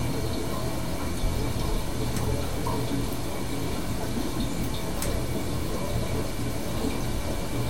bath6.ogg